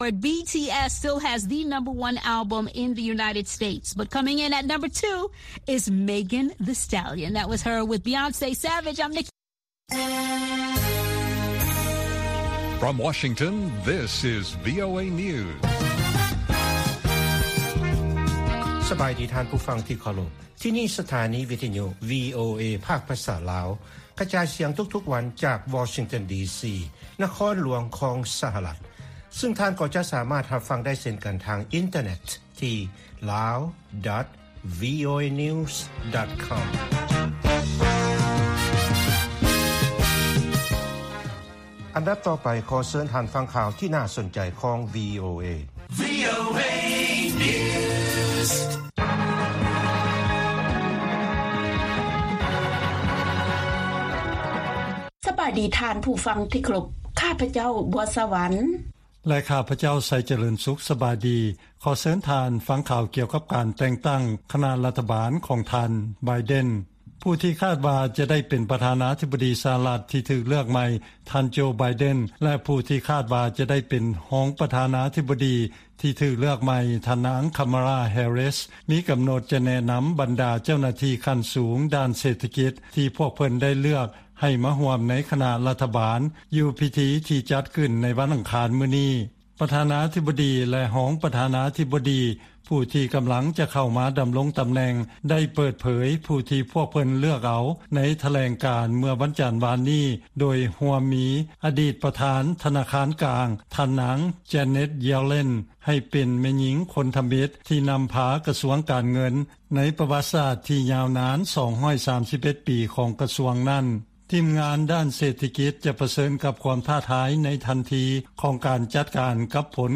ວີໂອເອພາກພາສາລາວ ກະຈາຍສຽງທຸກໆວັນ. ຫົວຂໍ້ຂ່າວສໍາຄັນໃນມື້ນີ້ມີ: 1) ອອສເຕຣເລຍ ປະທະກັບ ຈີນ ກ່ຽວກັບ ການເຜິຍແຜ່ ຮູບພາບປອມແປງ ອາຊະຍາກຳສົງຄາມ ລົງໃນສື່ສັງຄົມ.